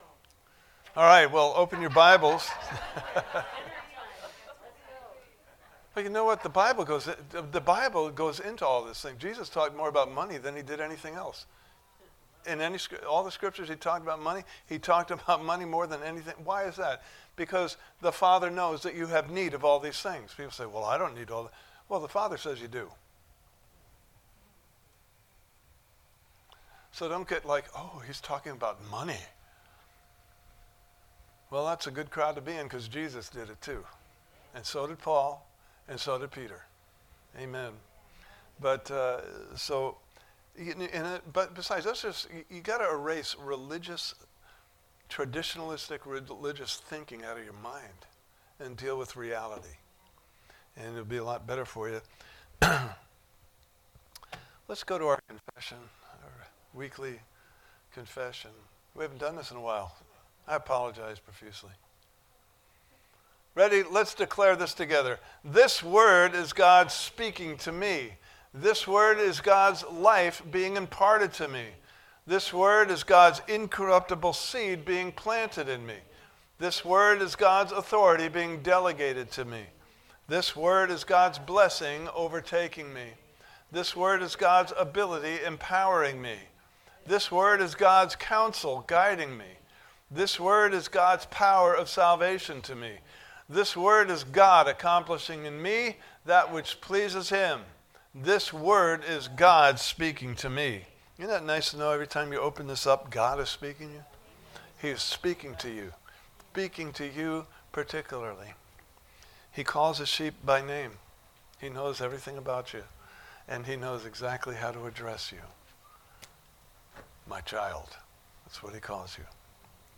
Service Type: Sunday Morning Service « Part 4: Jesus Has Given Angels Charge Over Us!